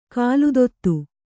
♪ kāludottu